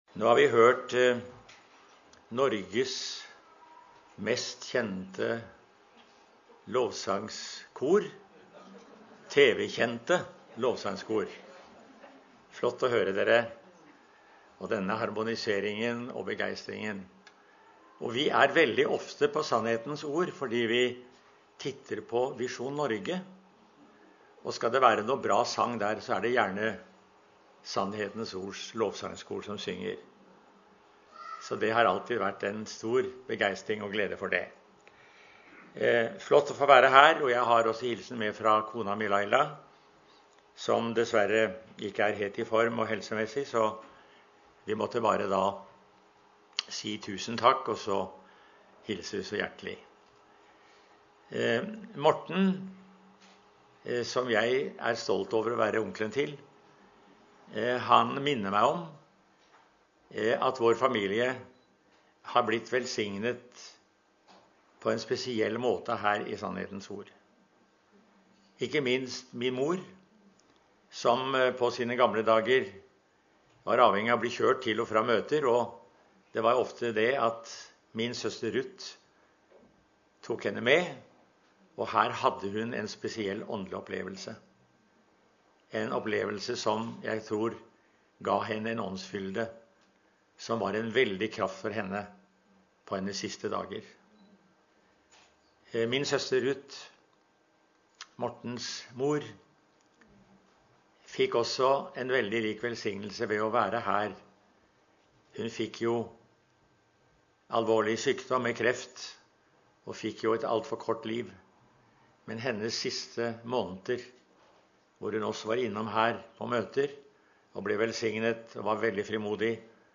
Søndagsgudstjeneste 2019